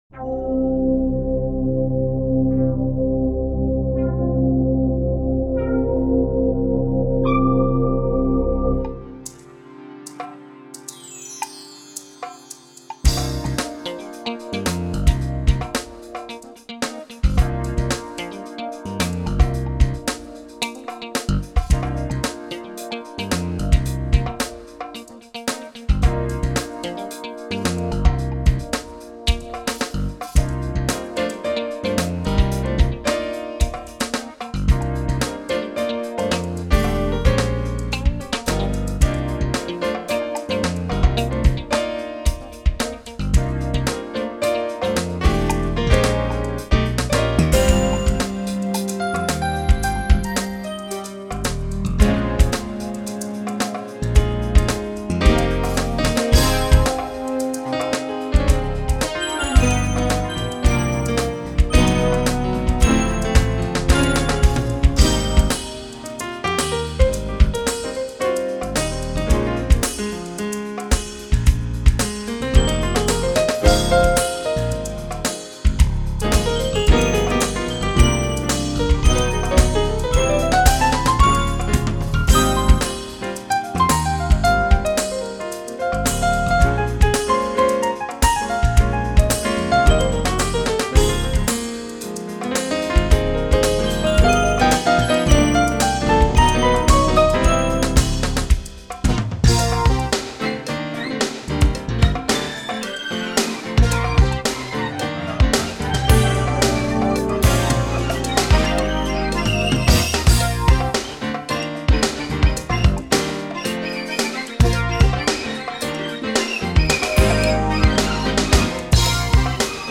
Acoustic Modeling with SX-WSA1R
technics_sx-wsa1r_-_factory_demo_-_synth_novlmodl.mp3